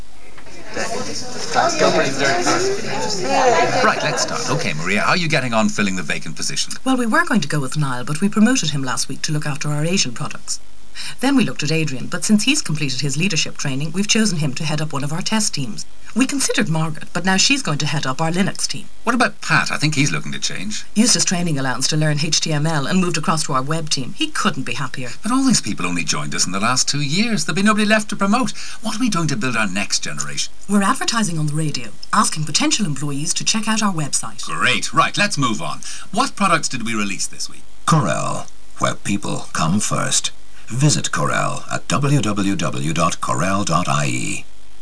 Radio Advert
This radio advert appeared over the Christmas period on Today FM as part of a campaign to attract emigrants returning home from Christmas.